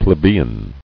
[ple·be·ian]